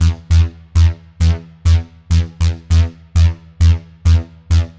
Dance music bass loops 2
Dance music bass loop - 100bpm 47